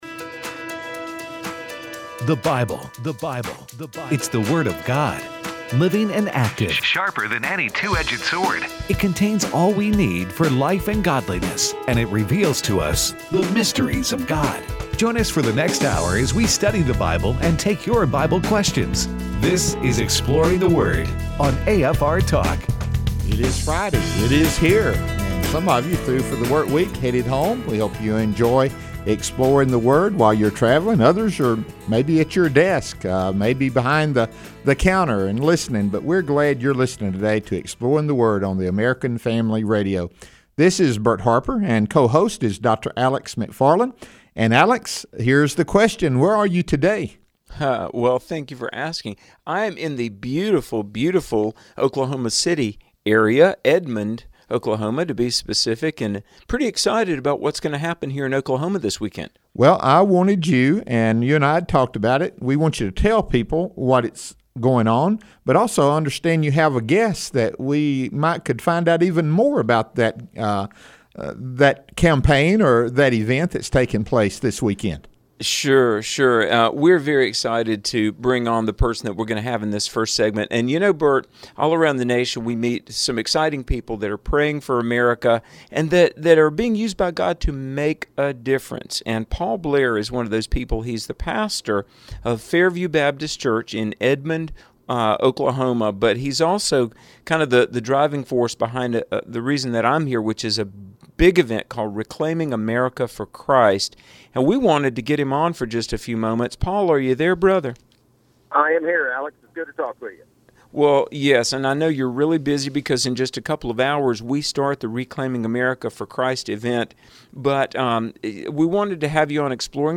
take your phone calls